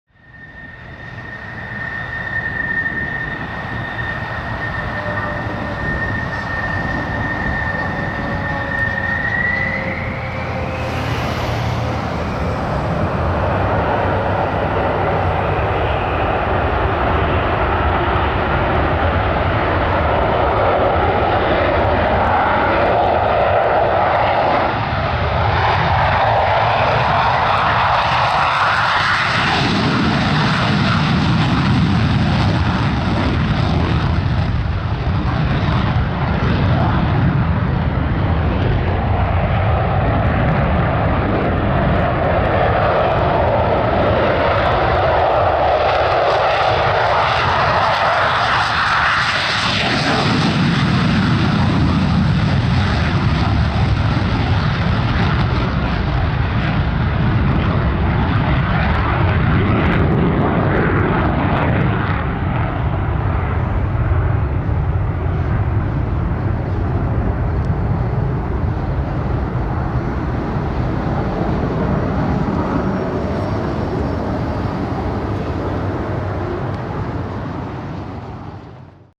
Звуки турбины самолета
Реактивный двигатель для сверхзвукового самолета